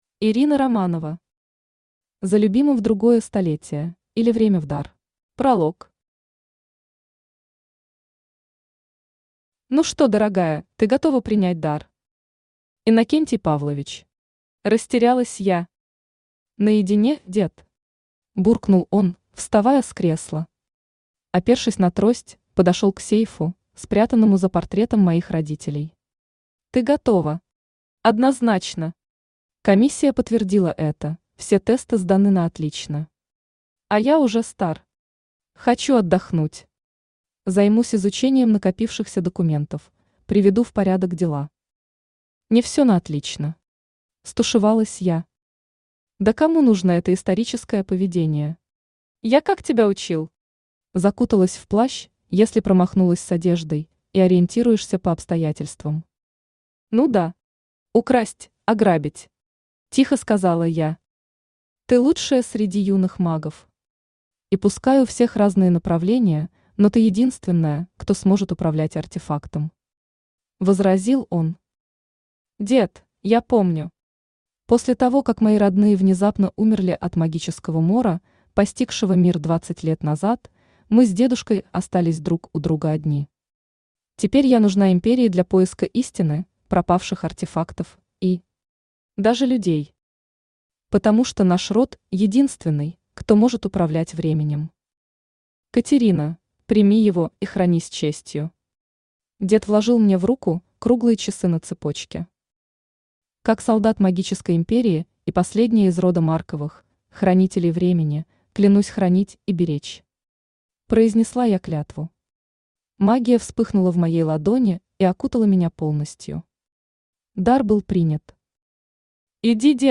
Аудиокнига За любимым в другое столетие, или время в дар | Библиотека аудиокниг
Aудиокнига За любимым в другое столетие, или время в дар Автор Ирина Романова Читает аудиокнигу Авточтец ЛитРес.